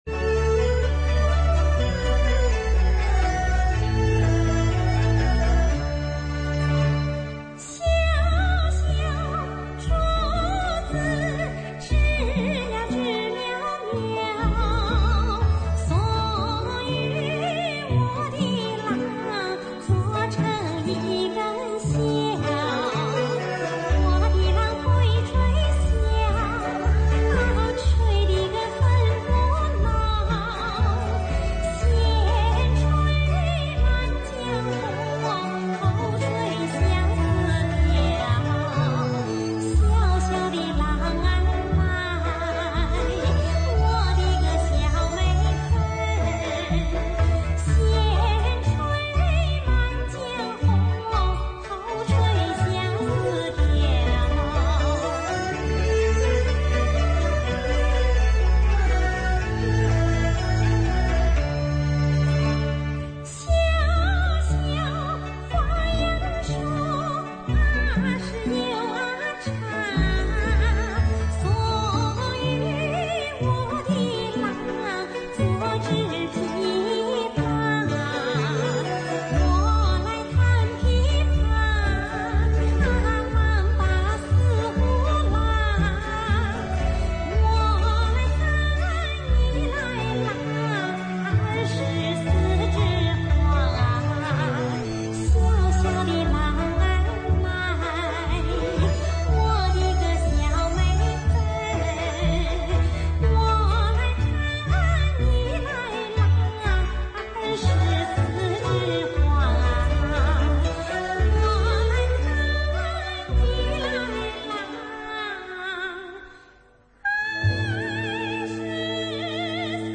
[5/6/2009]江苏民歌《八段景》 激动社区，陪你一起慢慢变老！